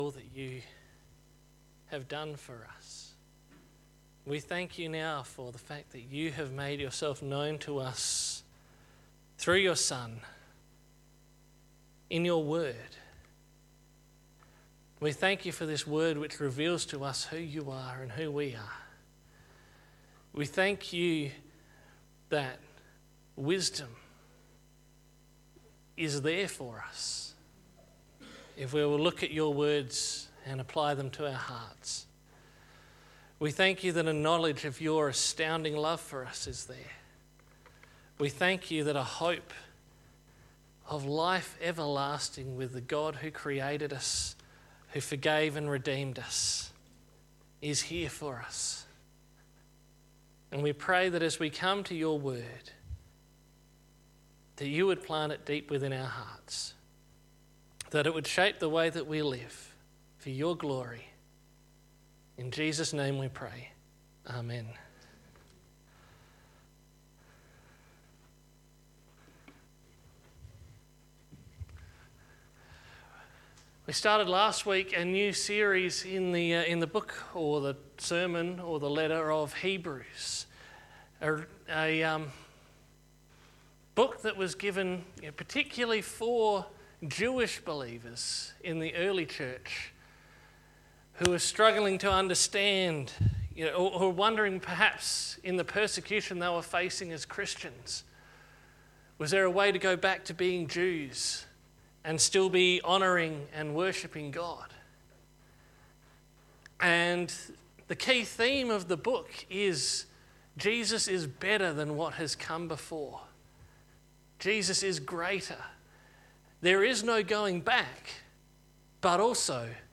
Sermons by Birdwood United Church